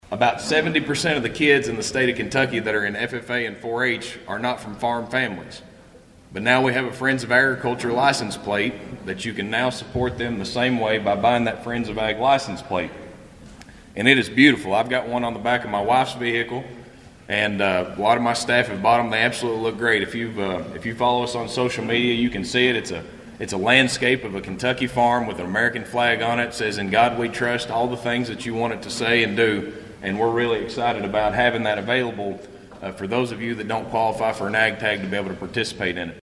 Agriculture Commissioner Shell Keynote Speaker At Christian County Salute To Agriculture Breakfast